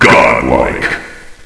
flak_m/sounds/announcer/int/godlike.ogg at 602a89cc682bb6abb8a4c4c5544b4943a46f4bd3
godlike.ogg